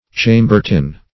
Chambertin \Cham`ber*tin"\, n. A red wine from Chambertin near Dijon, in Burgundy.